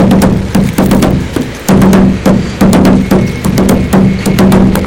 6 anni Indizi dei bambini È un “suono basso ritmo”, si può chiamare anche “tum tum cart” . Si fa con uno schermo da “battare” con le mani, che è come un tamburo della città.